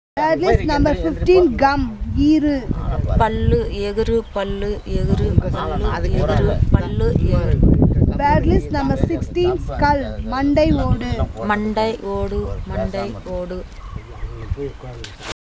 Elicitation of words about human body parts - Part 4